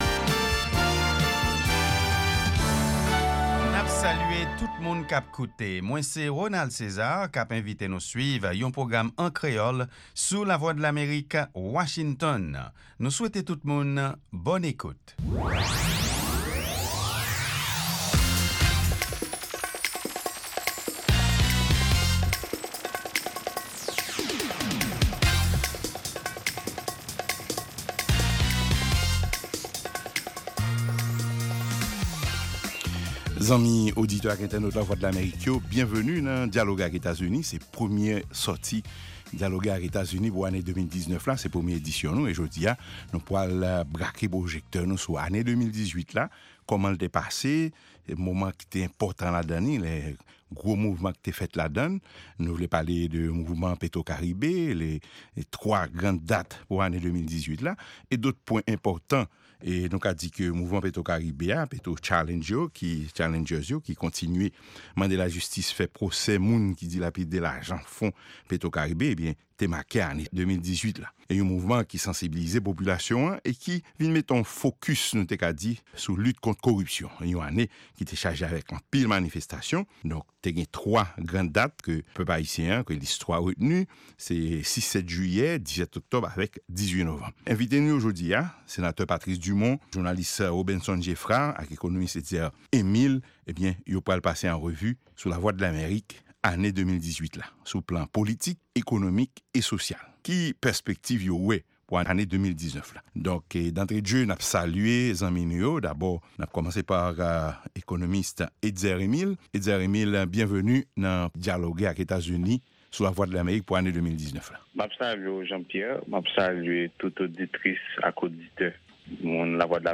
Se 3èm e dènye pwogram jounen an, avèk nouvèl tou nèf sou Lèzetazini, Ayiti ak rès mond la. Pami segman ki pase ladan yo e ki pa nan lòt pwogram yo, genyen Lavi Ozetazini ak Nouvèl sou Vedèt yo.